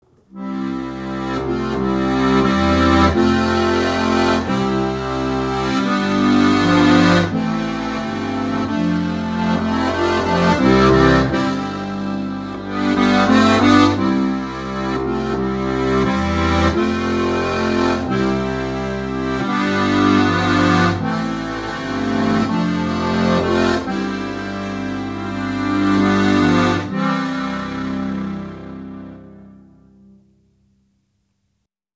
Diatonische Harmonikas
• 3-chörig
flaches Tremolo